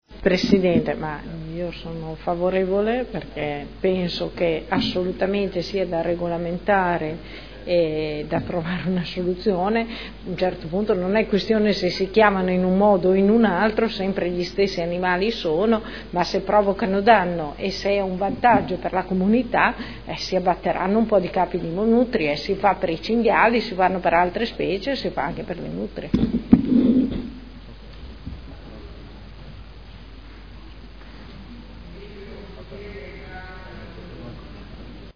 Seduta del 09/07/2015 Dichiarazione di voto. Approvazione convenzione per il controllo della specie nutria